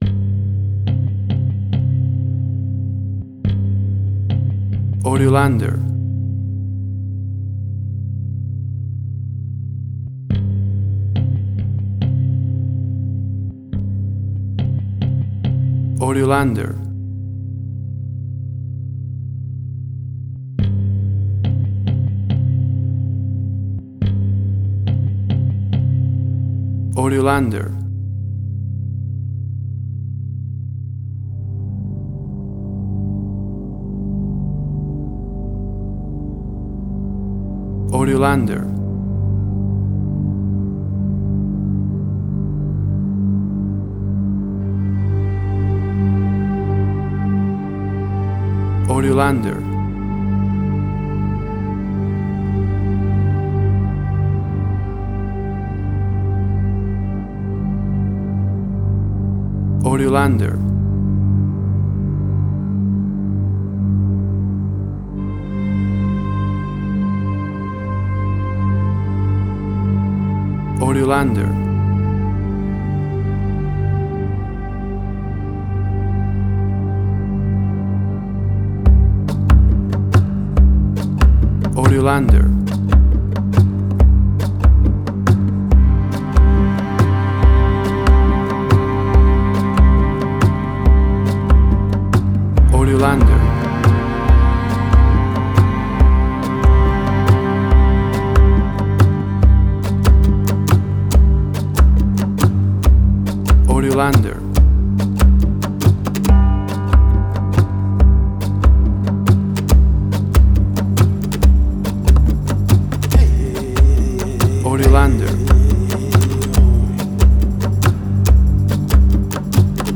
Spaghetti Western
ethnic voice and native percusion
Tempo (BPM): 140